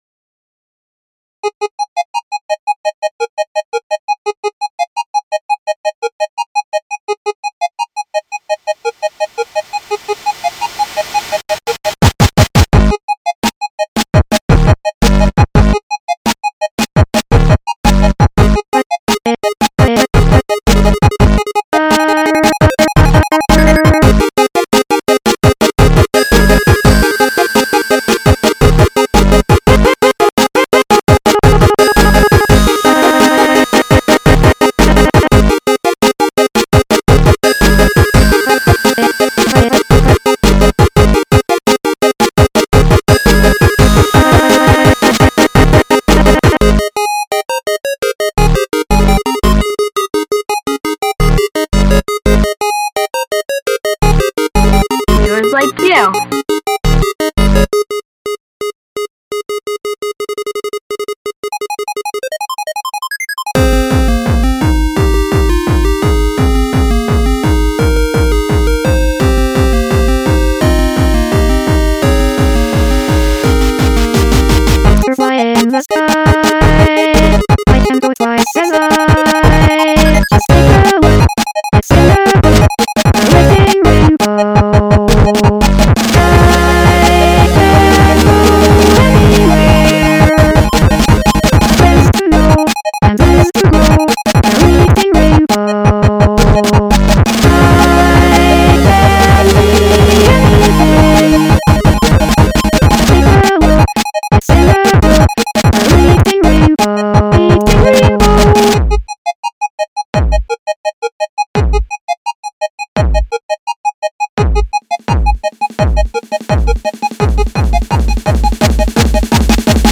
8-bit chiptunes version